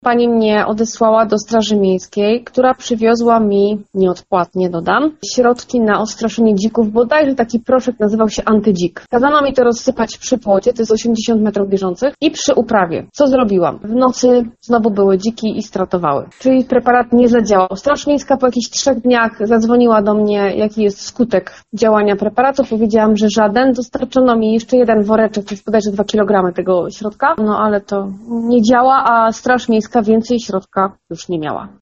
Posłuchaj Kobieta czuje się bezradna.